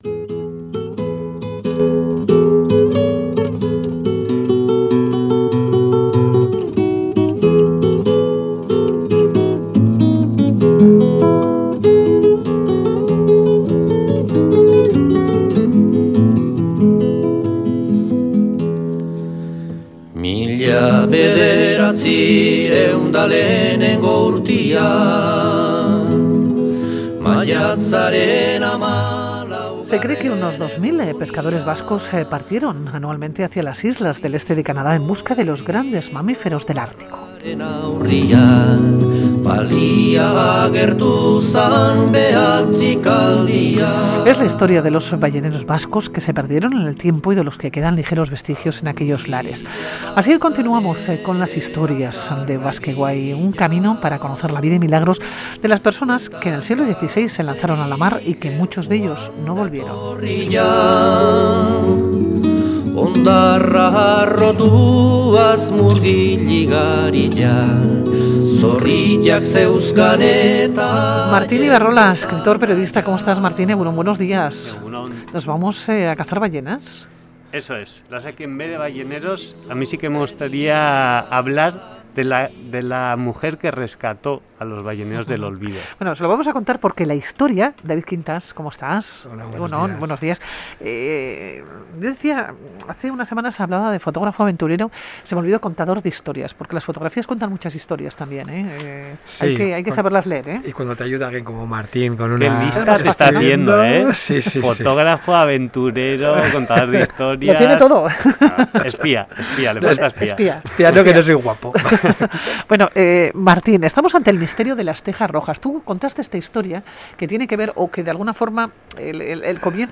Audio: Continuamos con las narraciones del 'Basque Way'. Cerca de dos mil pescadores vascos se echaron a la mar en el siglo XVI hacia las costas del este de Canadá en busca del gran cetáceo.